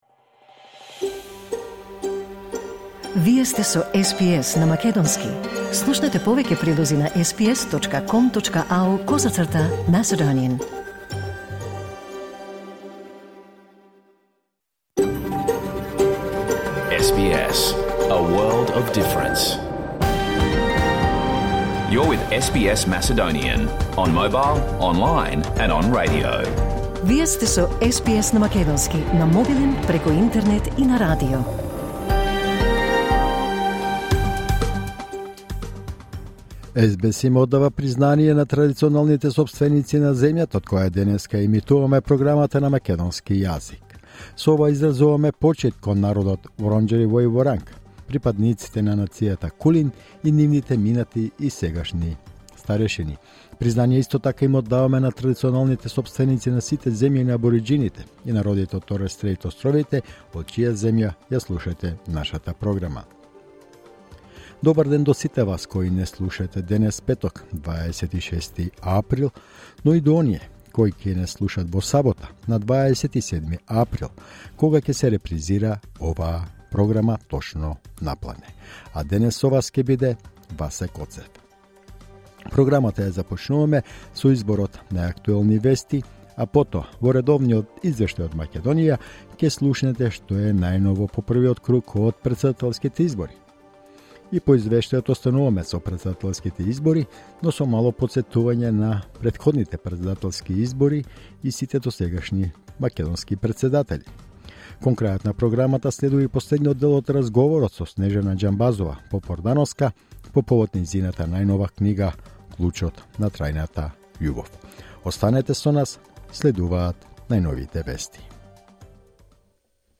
SBS Macedonian Program Live on Air 26 April 2024